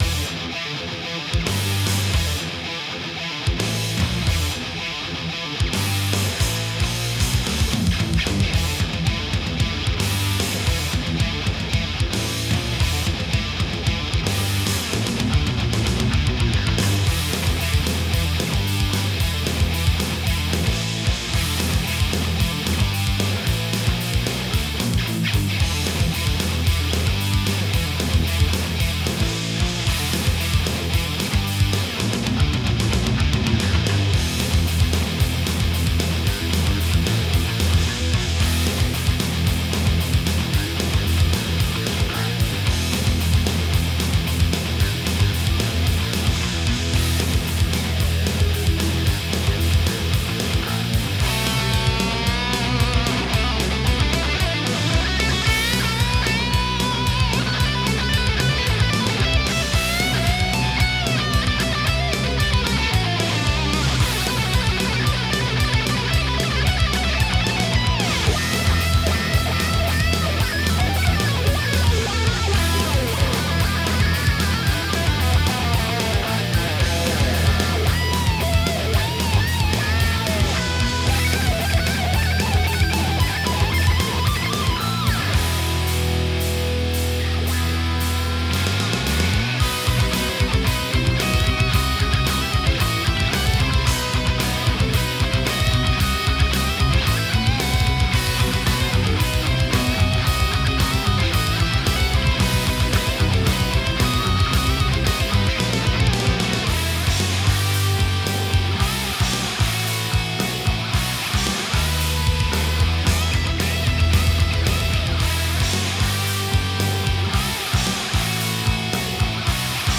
lead guitarmetalsoloscompositionscalestechnique
• Using Pentatonic Minor, Blues, and Minor scales